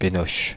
Ääntäminen
Synonyymit penalty tir au but Ääntäminen France (Île-de-France): IPA: /pe.noʃ/ Haettu sana löytyi näillä lähdekielillä: ranska Käännöksiä ei löytynyt valitulle kohdekielelle.